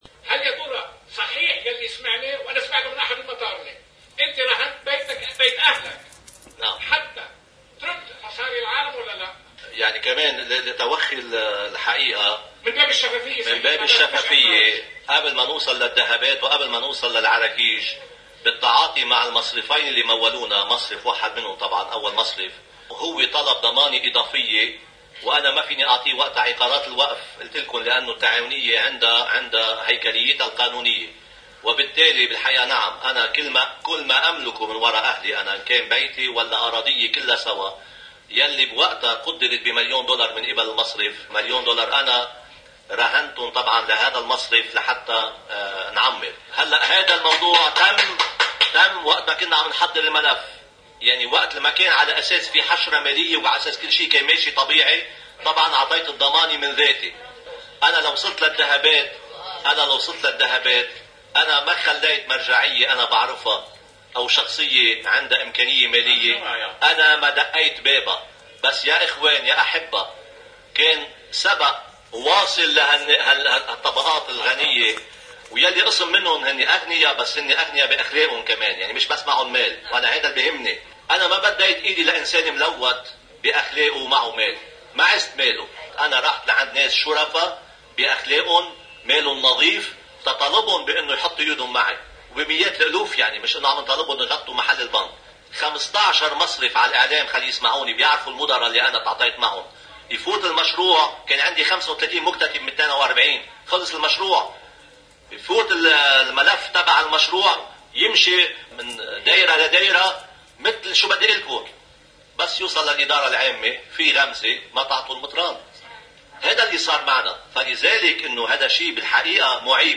ورفض المطران نصار، حماسة مؤيديه، طالباً منهم الهدوء وقال: